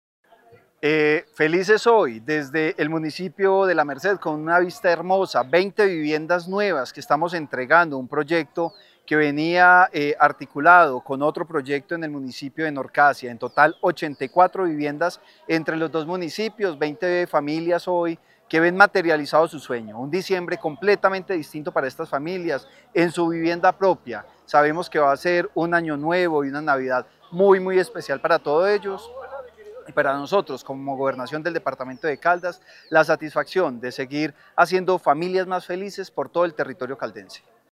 Francisco Javier Vélez Quiroga, secretario de Vivienda y Territorio de Caldas.